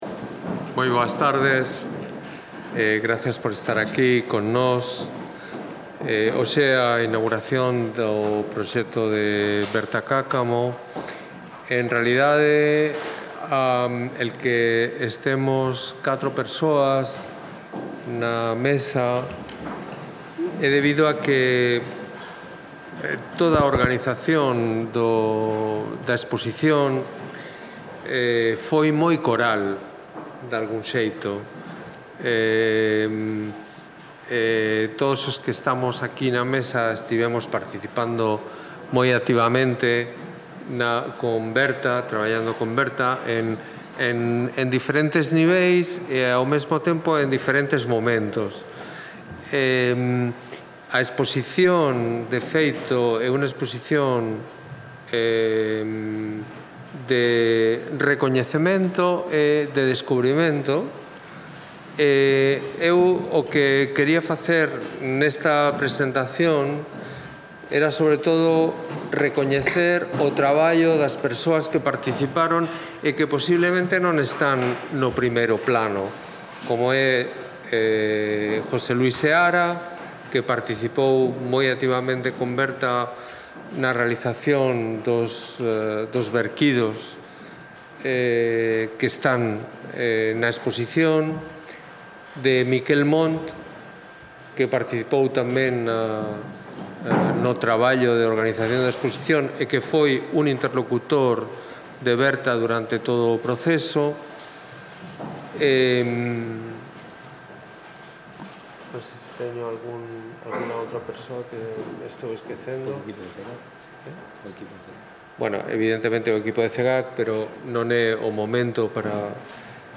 Folleto da exposición Audio da conferencia